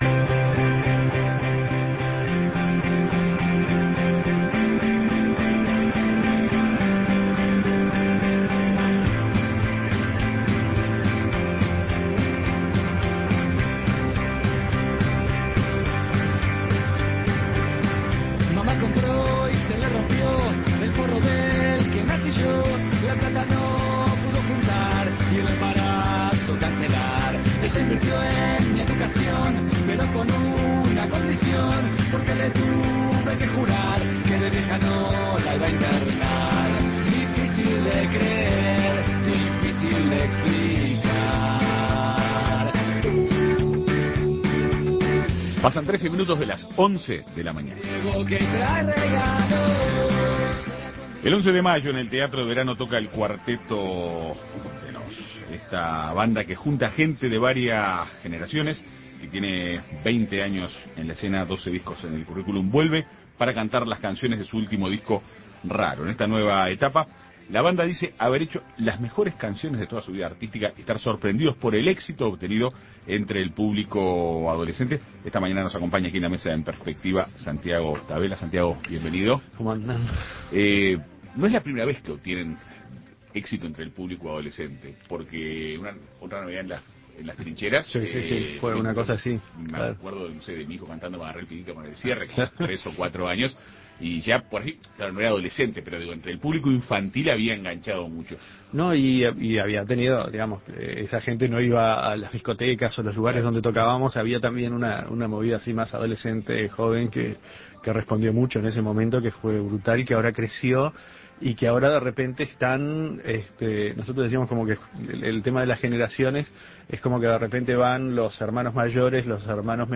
Entrevista a Santiago Tavella